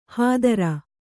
♪ hādara